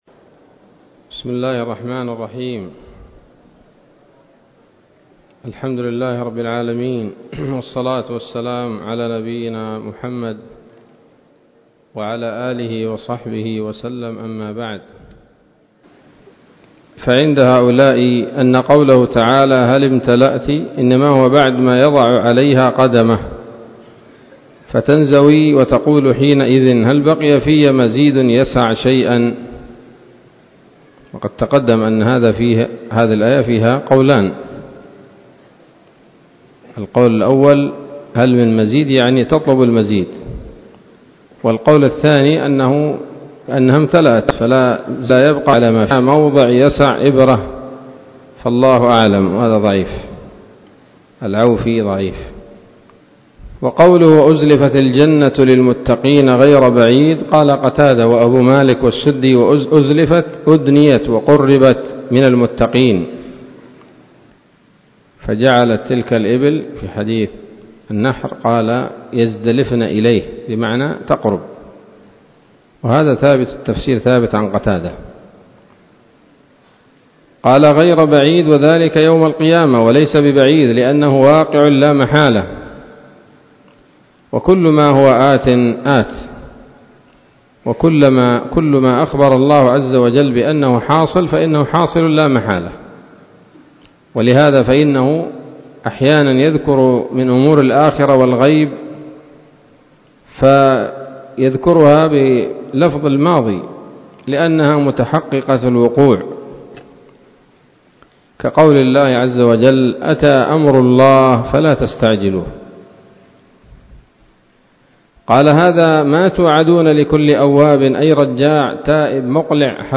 الدرس السابع من سورة ق من تفسير ابن كثير رحمه الله تعالى